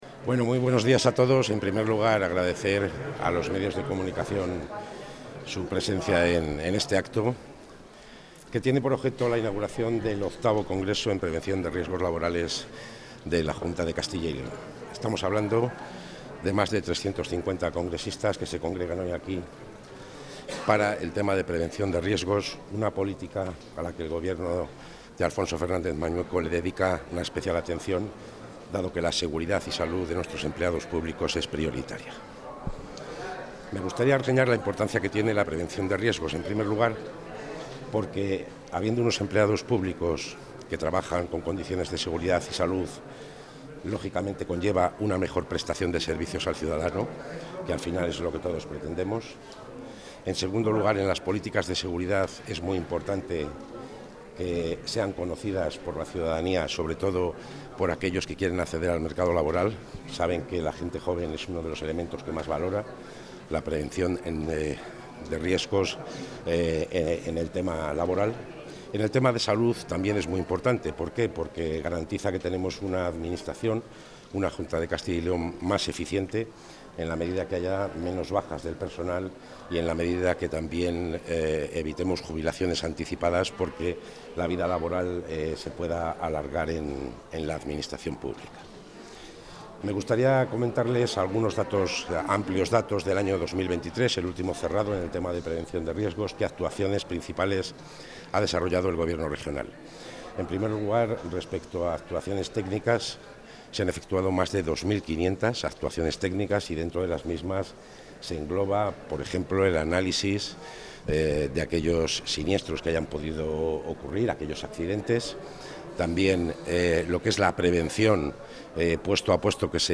Intervención del consejero.
El consejero de la Presidencia, Luis Miguel González Gago, ha inaugurado el VIII Congreso de Prevención de Riesgos Laborales en la Administración Pública, que reúne en la Feria de Valladolid a más de 350 personas expertas o interesadas por este sector, con una especial atención a la importancia de la formación de los empleados públicos, a las necesidades específicas de seguridad y salud laboral para las mujeres, y a las nuevas investigaciones en torno a los riesgos psicosociales derivados del trabajo.